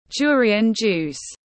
Nước ép sầu riêng tiếng anh gọi là durian juice, phiên âm tiếng anh đọc là /ˈdʒʊə.ri.ən ˌdʒuːs/
Durian juice /ˈdʒʊə.ri.ən ˌdʒuːs/